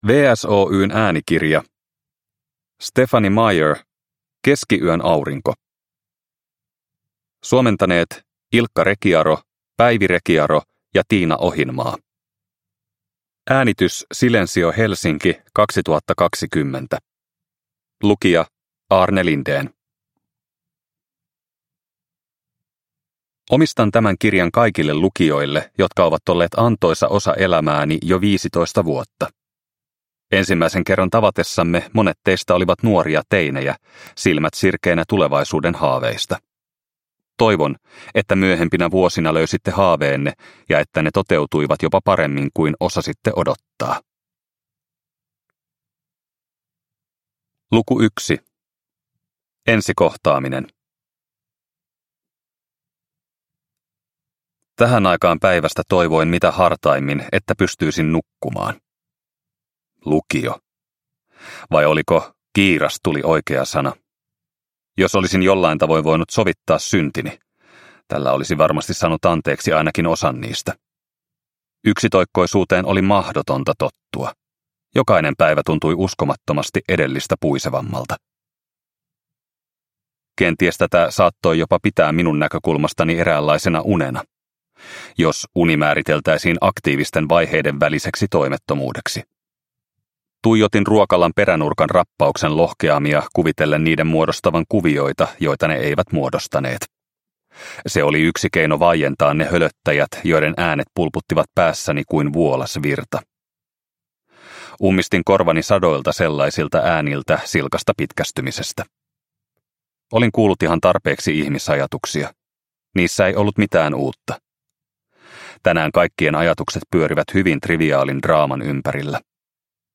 Keskiyön aurinko – Ljudbok – Laddas ner